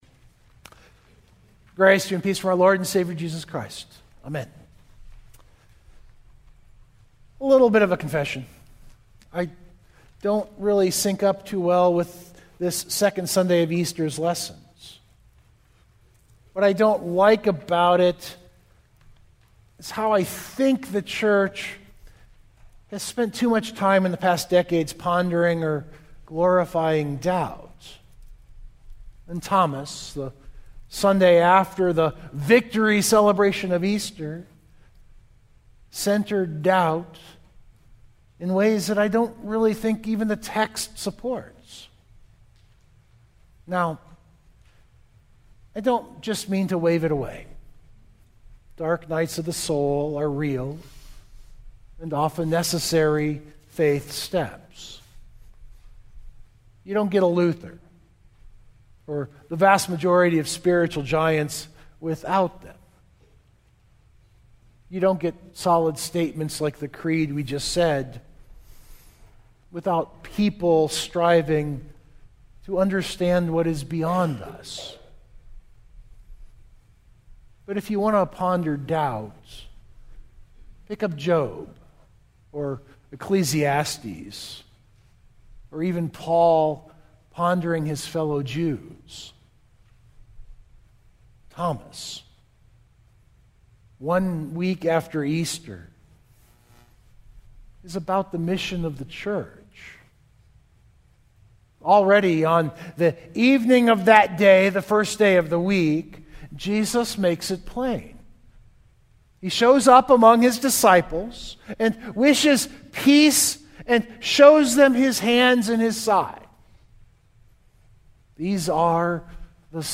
Mt. Zion Lutheran - Sermons